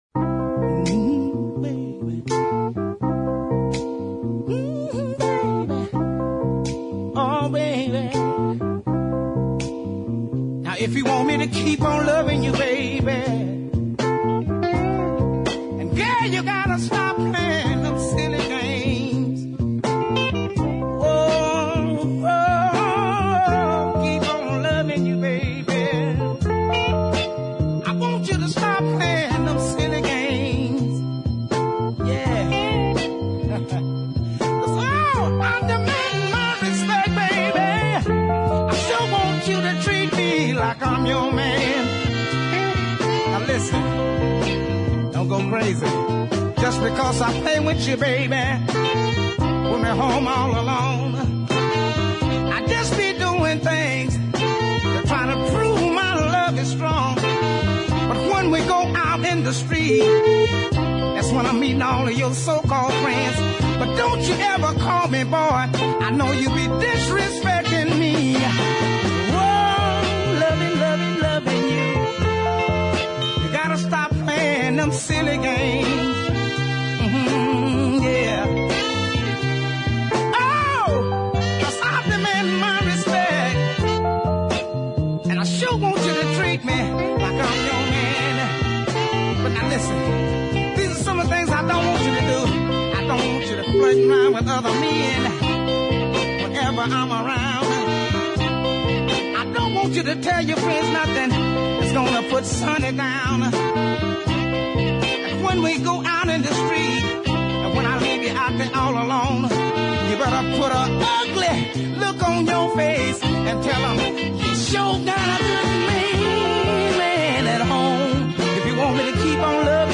West Coast soul/blues style
is a lovely blue soul item